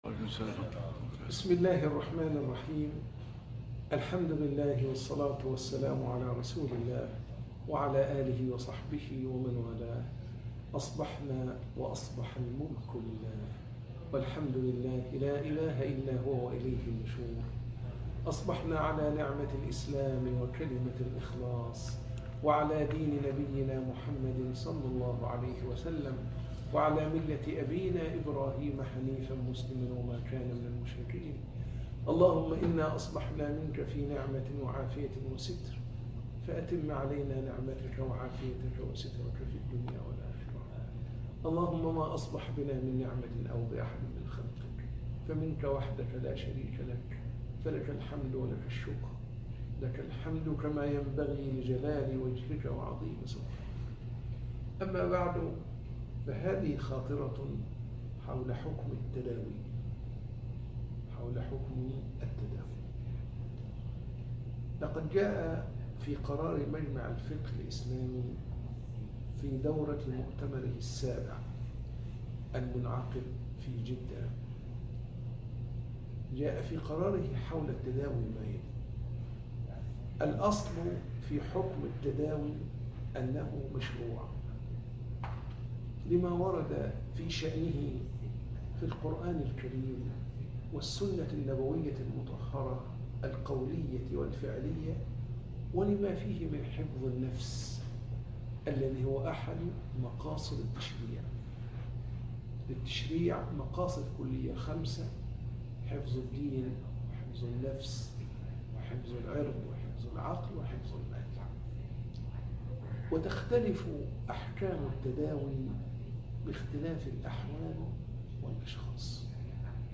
درس بعد الفجر